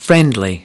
20 friendly (adj) /ˈfrendli/ Thân thiện